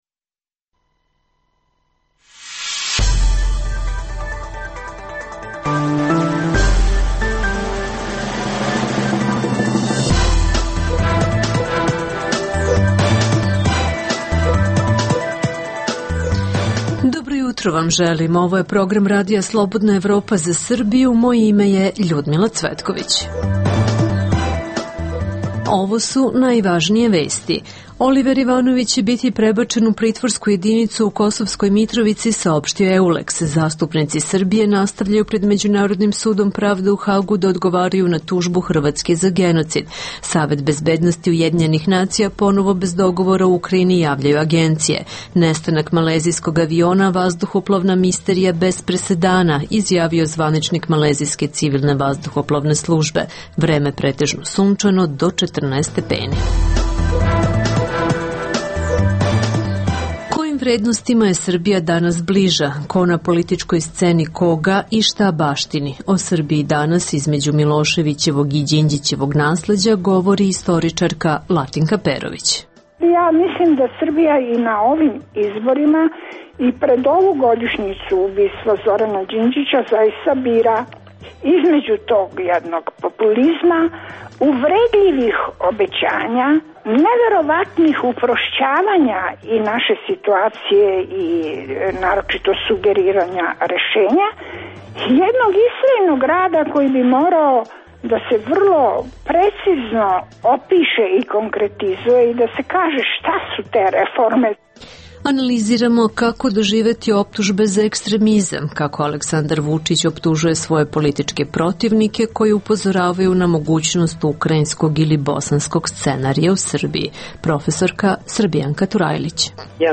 O Srbiji, danas izmedju Miloševićevog i Đinđićevog nasledja, govori istoričarka Latinka Perović.